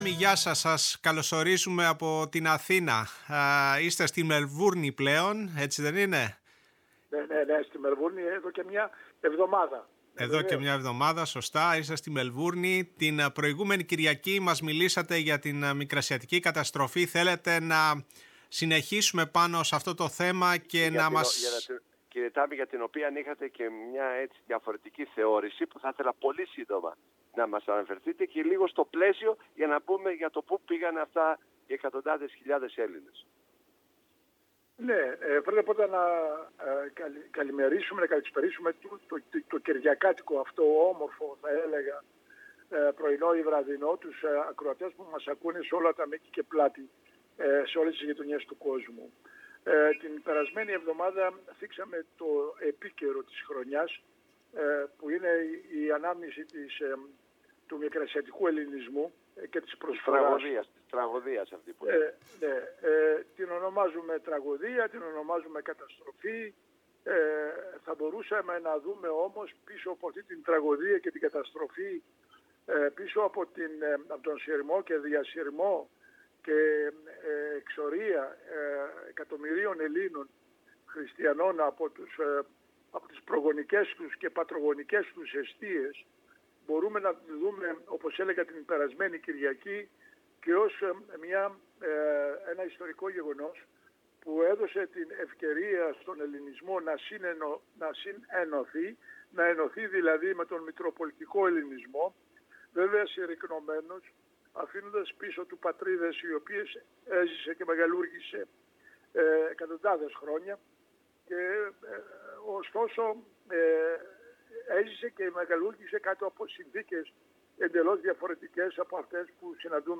στην εκπομπή «Η Παγκόσμια Φωνή μας» στο ραδιόφωνο της Φωνής της Ελλάδος